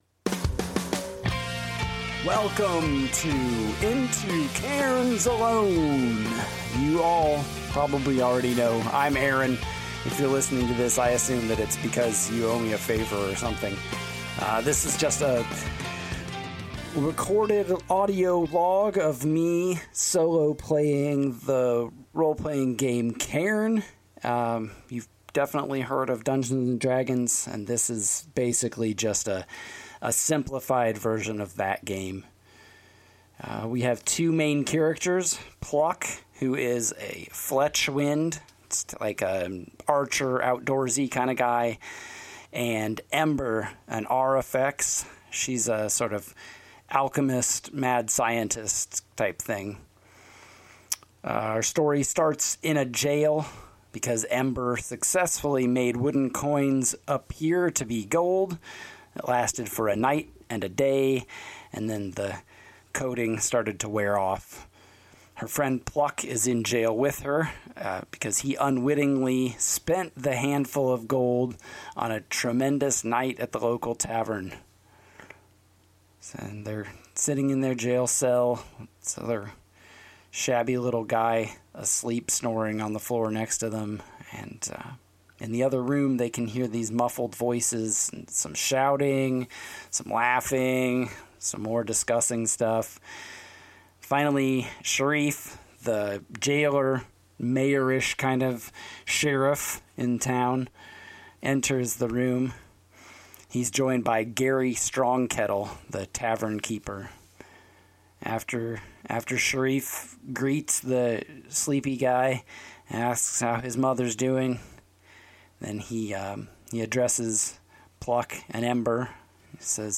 Cairn 2e solo actual play using Highland Paranormal Society's Oracle of the Mantis Knight.